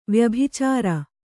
♪ vyabhicāra